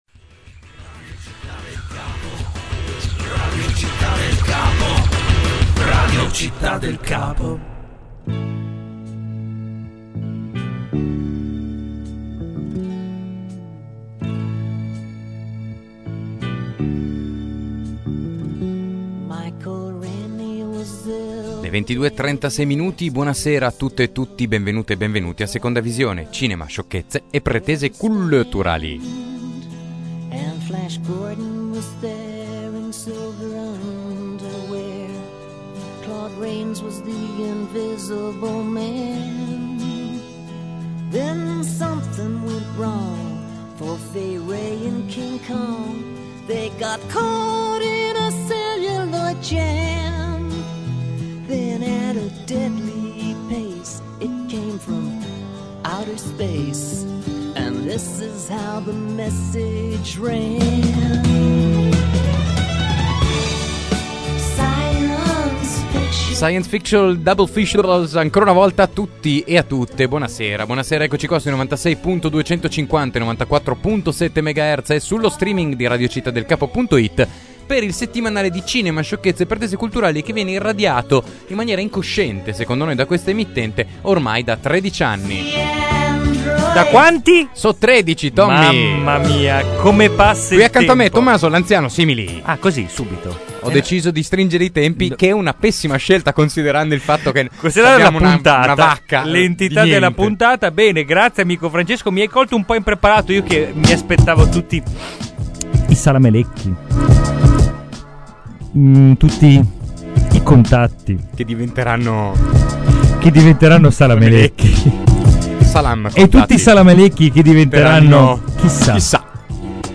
un’intervista sul Future Film Festival